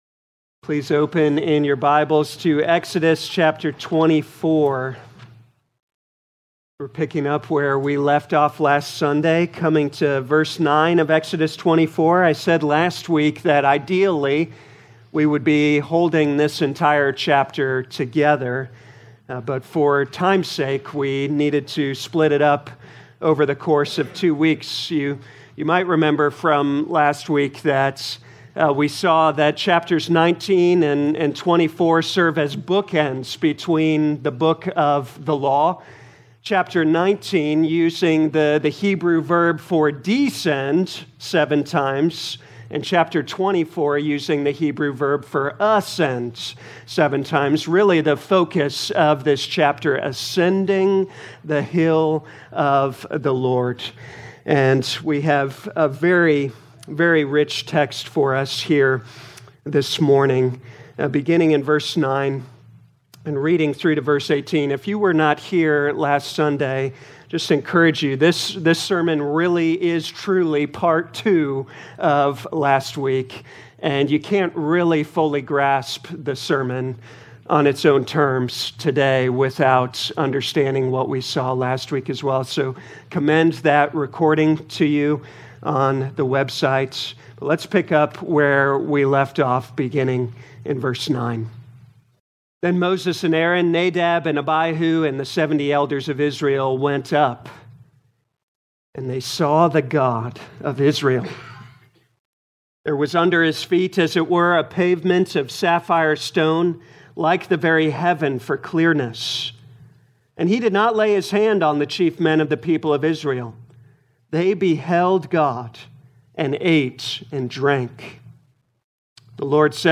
2025 Exodus Morning Service Download